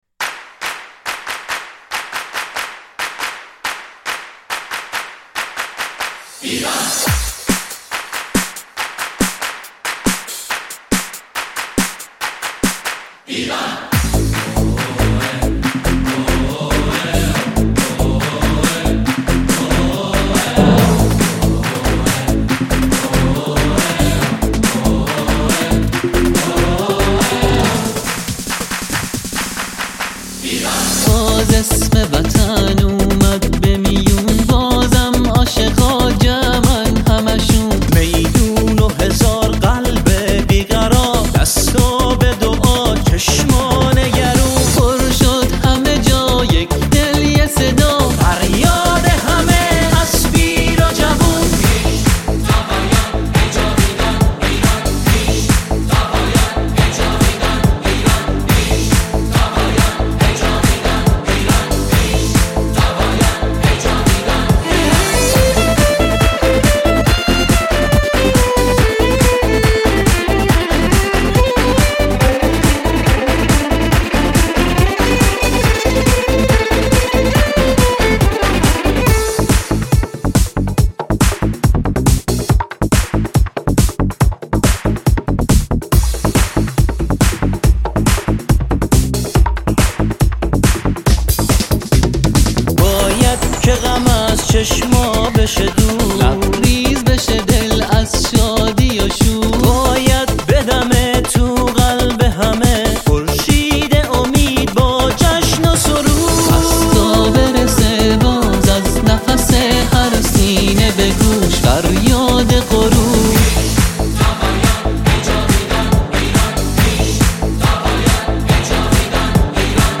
سرودهای ورزشی
خوانندگان، این قطعه را با شعری ورزشی اجرا می‌کنند.